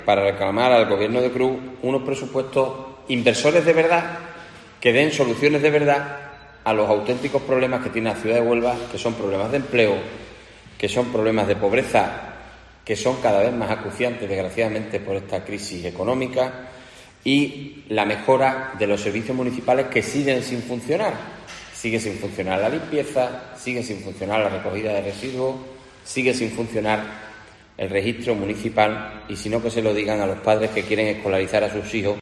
Jaime Pérez, portavoz PP Ayuntamiento de Huelva